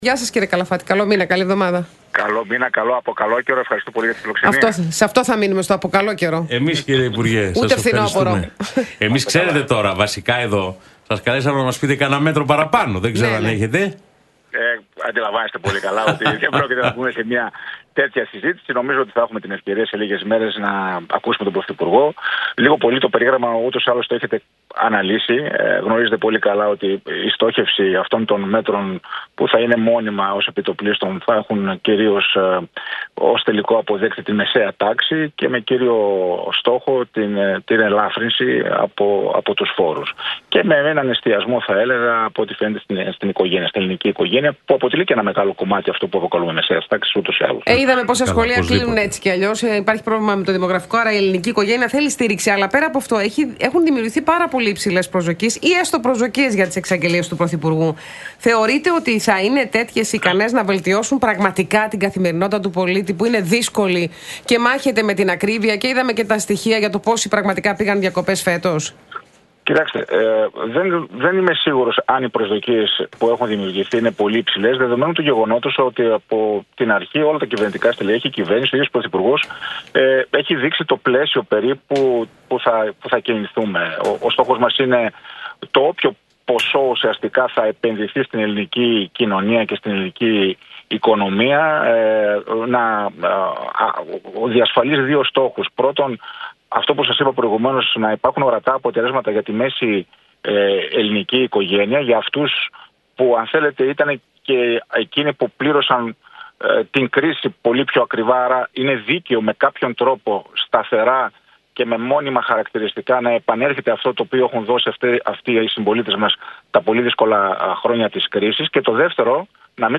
Καλαφάτης στον Realfm 97,8: «Η κυβέρνηση στηρίζει την κοινωνία μέσα από την ανάπτυξη, τη μείωση της ανεργίας και την καταπολέμηση της φοροδιαφυγής»